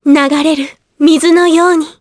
Laias-Vox_Victory_jp.wav